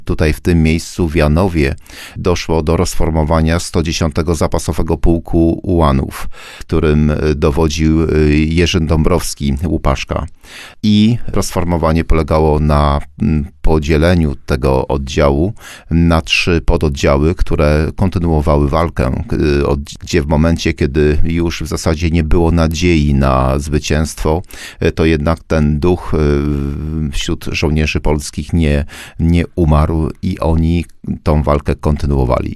Wójt gminy Kolno, Józef Wiśniewski mówi, że to wspaniała uroczystość patriotyczna.